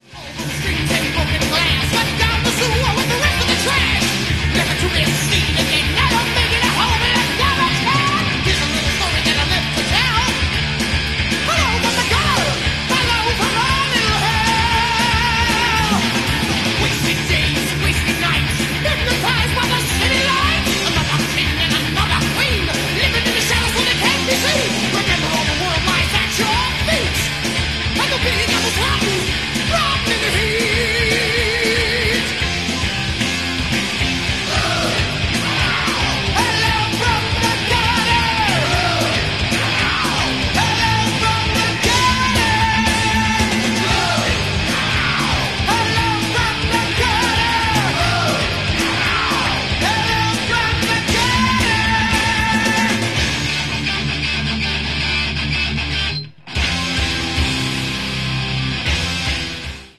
Genre:Thrash Metal